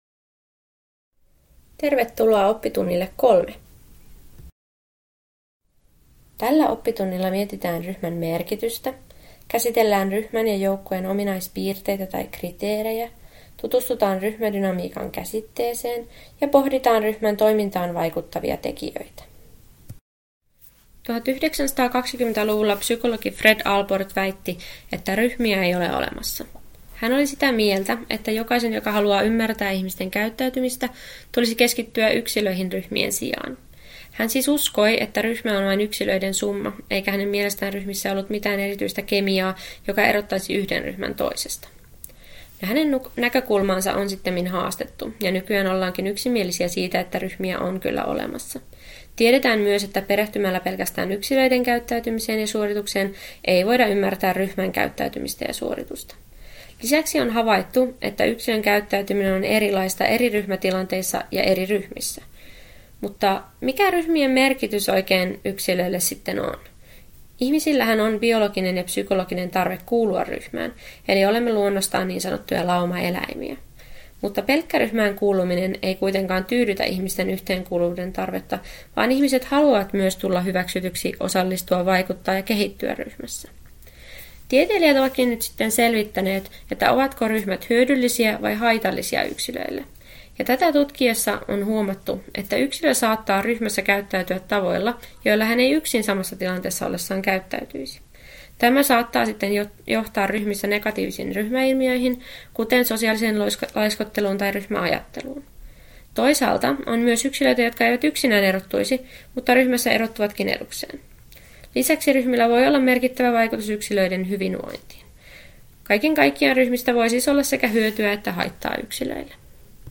Oppitunti 3: Yleiskatsaus liikuntaryhmiin ja ryhmädynamiikkaan — Moniviestin